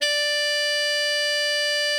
bari_sax_074.wav